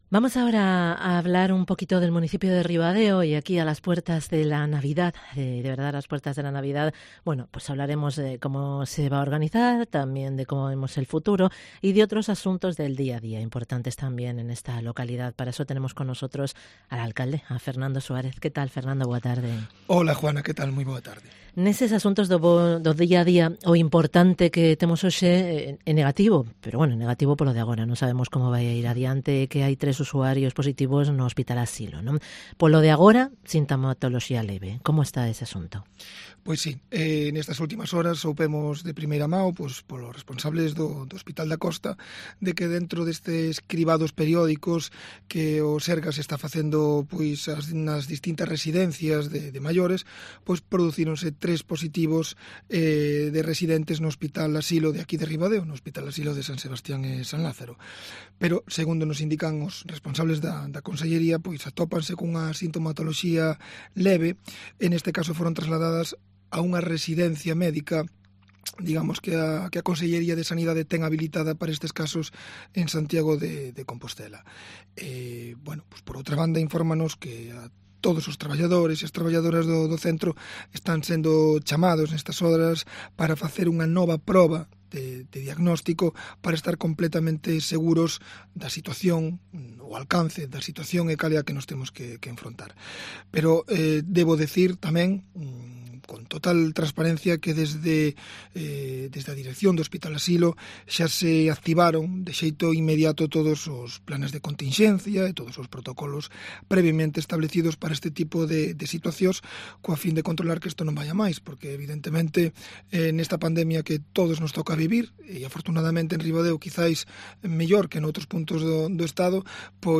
Entrevista con FERNANDO SUÁREZ, alcalde de Ribadeo